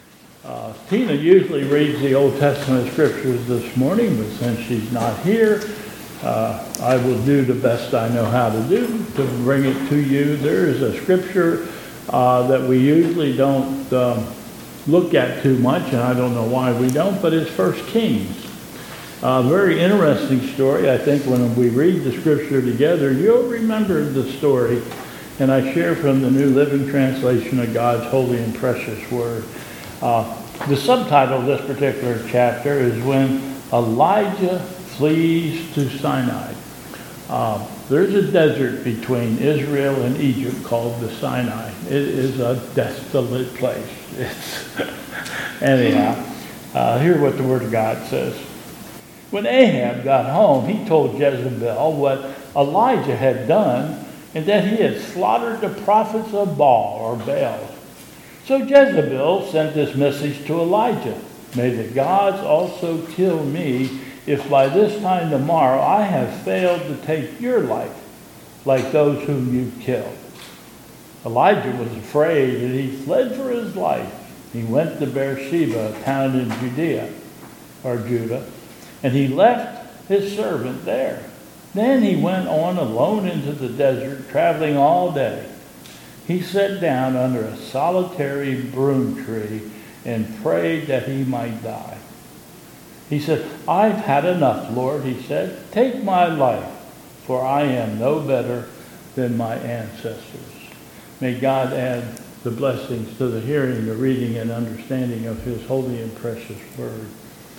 OT Scripture Reading